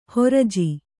♪ horaji